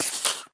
mob / creeper4